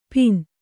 ♪ pin